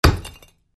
На этой странице собраны разнообразные звуки, связанные с кирпичами: от стука при строительстве до грохота падения.
Кирпич - Альтернативный вариант 2